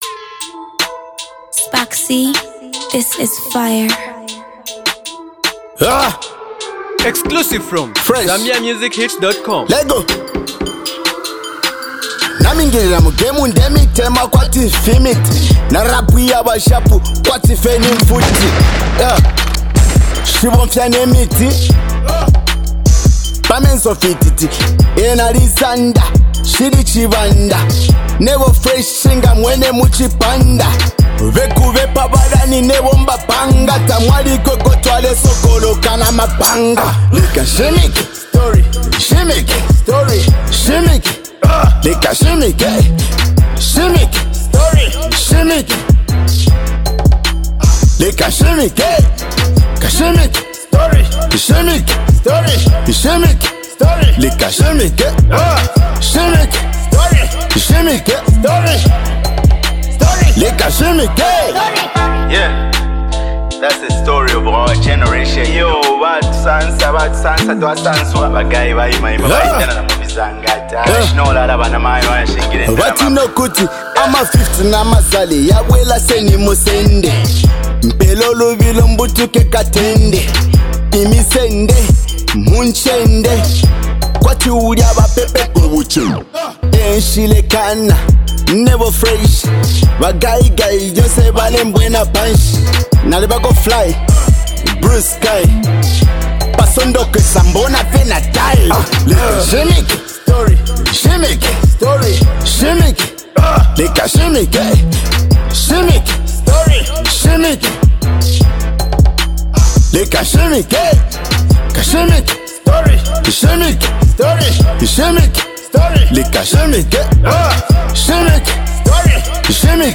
Zambian rapper and music star